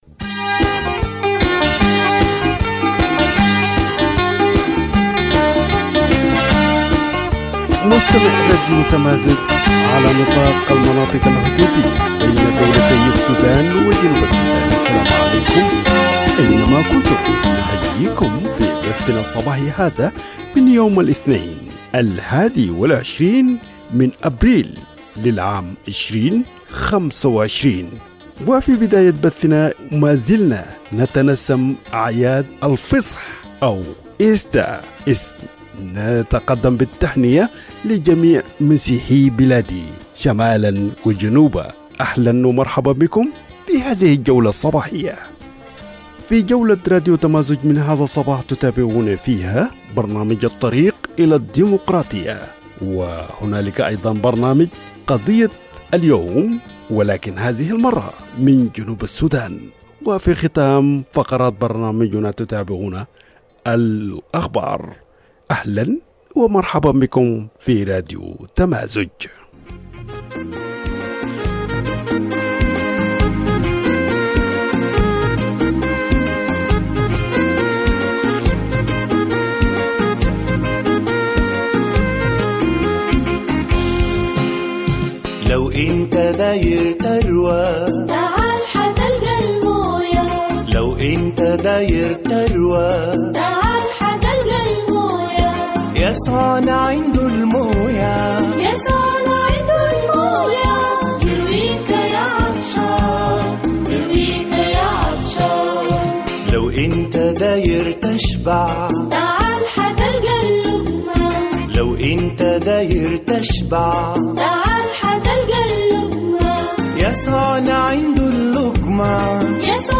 Morning Broadcast 21 April - Radio Tamazuj